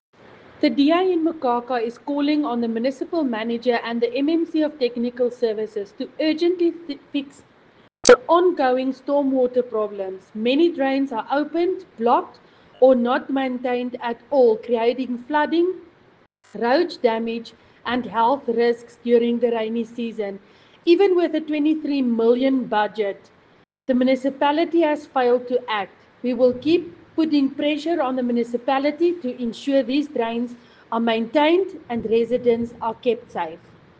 Afrikaans soundbites by Cllr Linda Louwrens and Sesotho soundbite by Cllr Kabelo Moreeng.